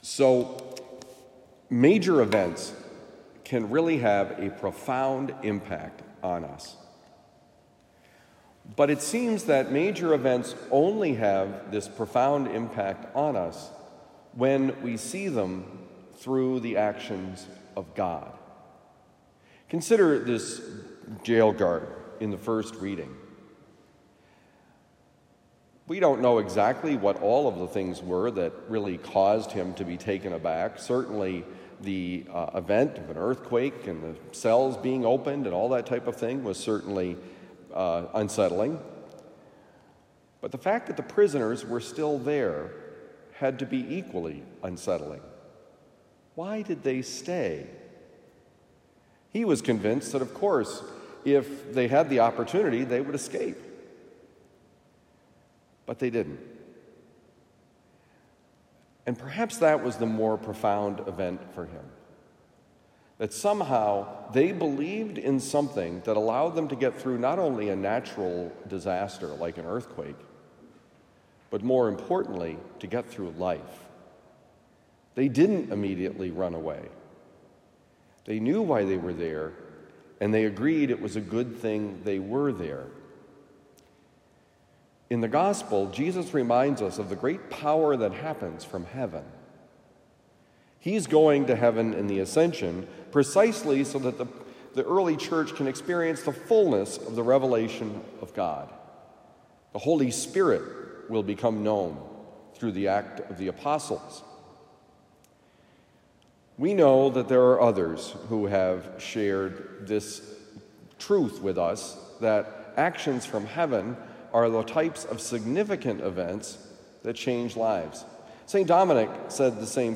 Major Events: Homily for Tuesday, May 16, 2023
Given at Christian Brothers College High School, Town and Country, Missouri.